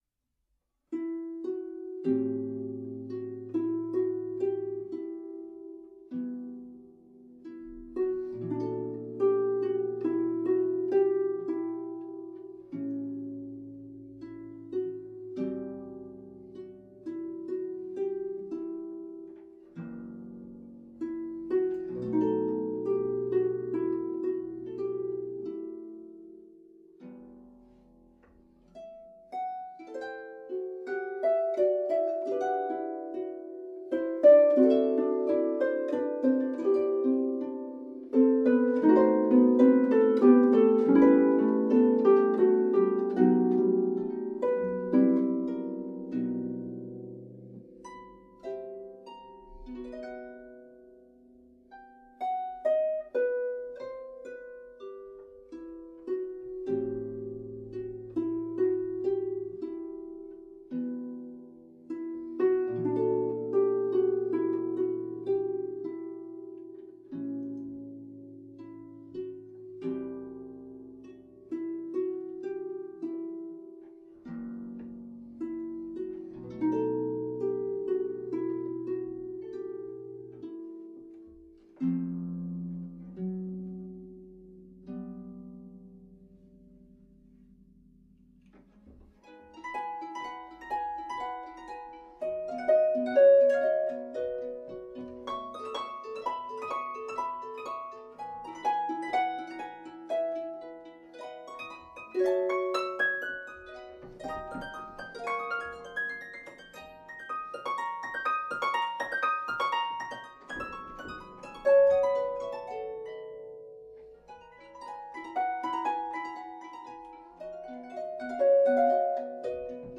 for solo pedal harp.